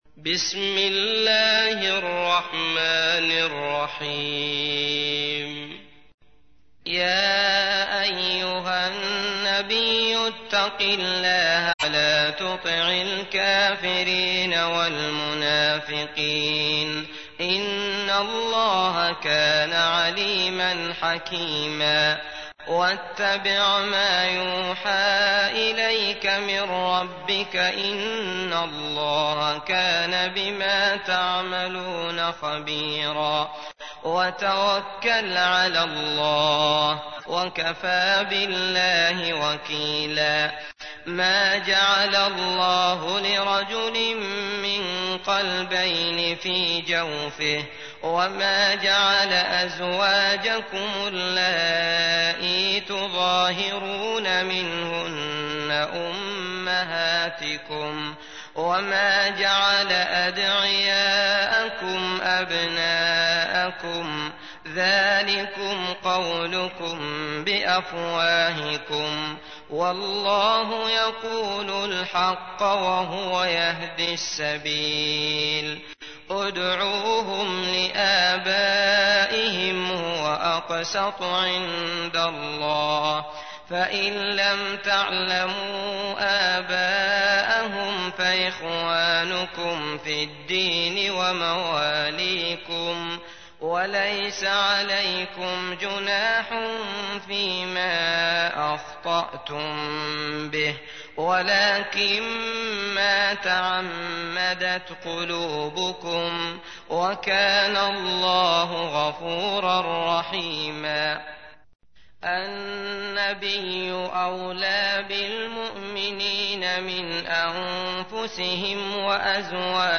تحميل : 33. سورة الأحزاب / القارئ عبد الله المطرود / القرآن الكريم / موقع يا حسين